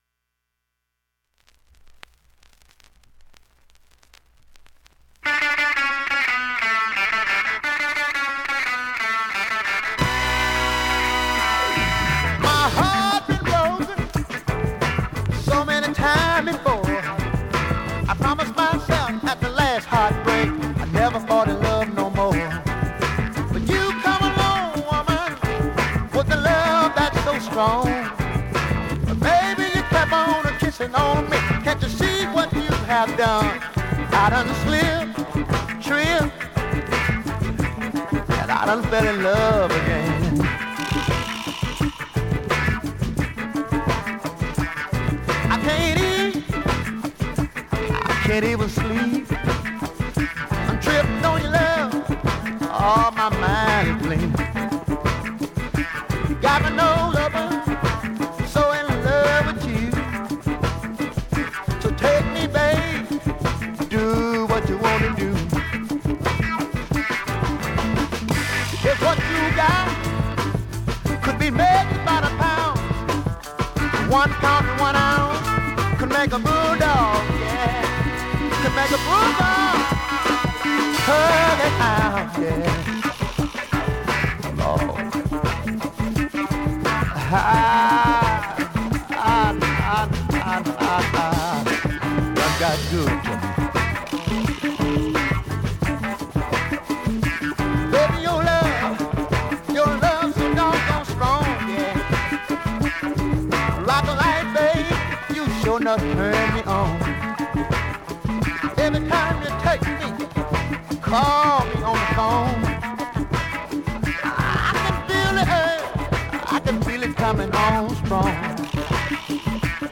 白厚紙ジャケット付属 ファンキーブルース